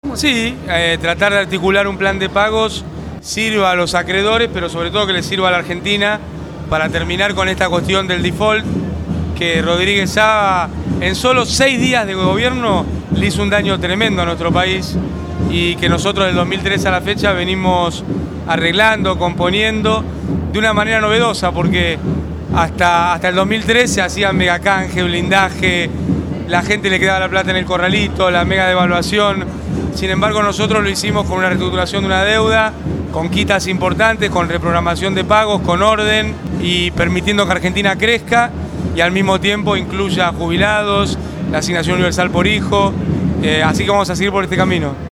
Al cumplirse un mes de su fallecimiento, La Cámpora organizó en la ciudad de La Plata un acto central en homenaje al ex Presidente.